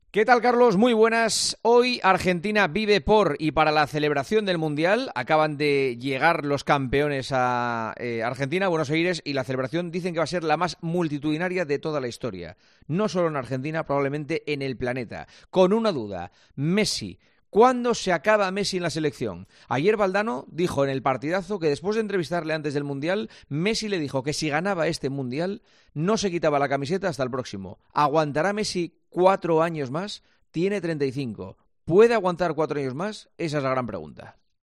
El director de 'El Partidazo de COPE' analiza la actualidad deportiva en 'Herrera en COPE'